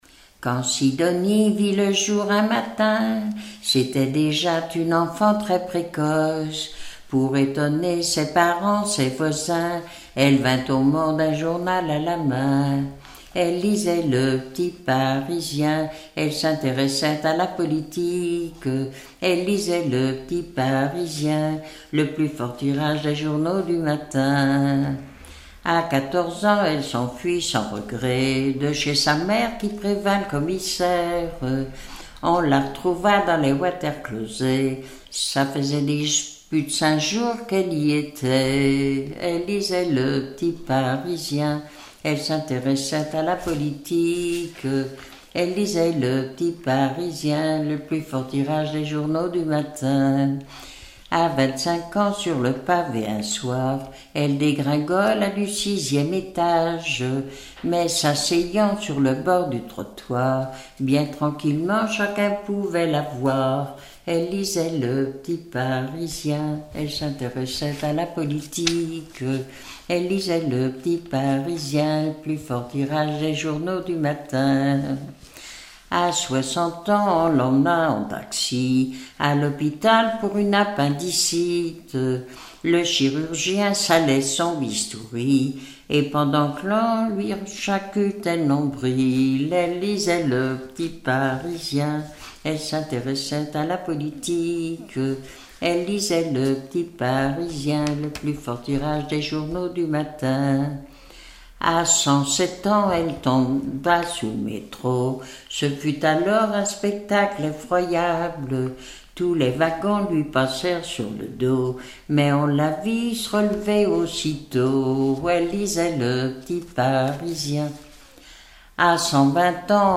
Saint-Vincent-Sterlange
Genre strophique
Pièce musicale inédite